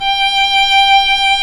Index of /90_sSampleCDs/Roland - String Master Series/STR_Viola Solo/STR_Vla1 % + dyn